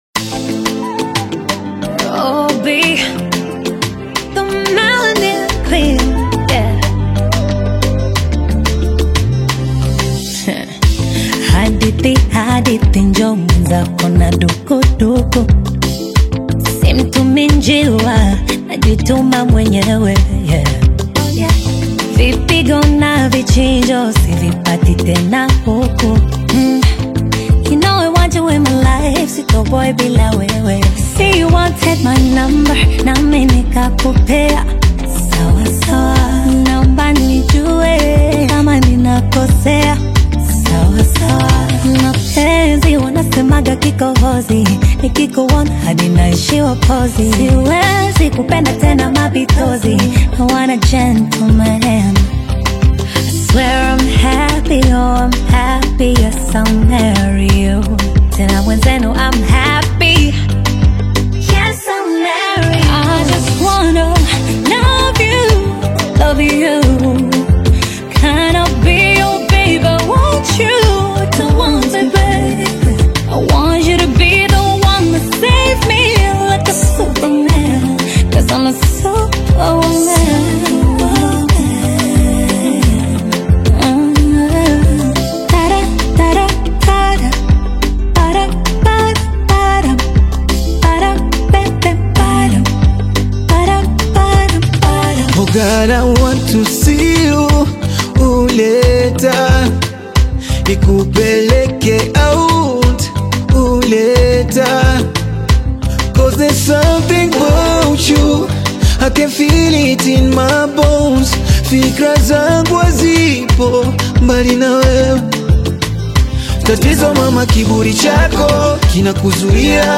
Tanzanian Bongo Fleva